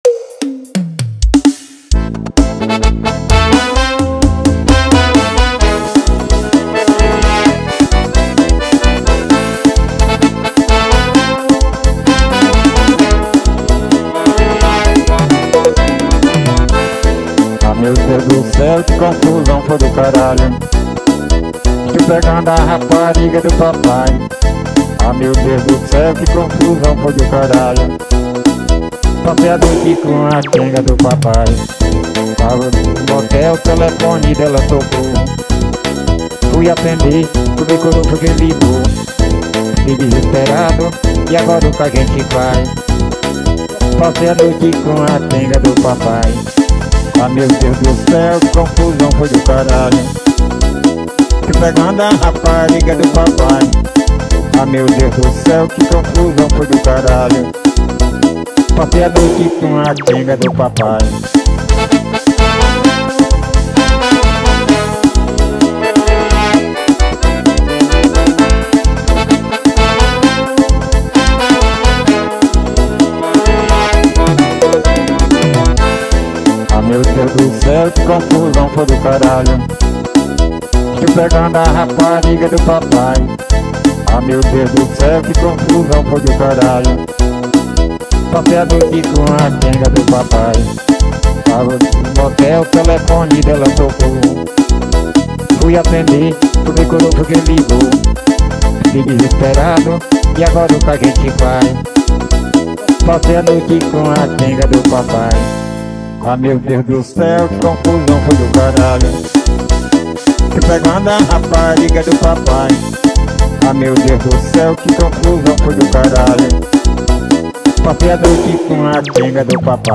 2024-12-20 11:27:48 Gênero: Forró Views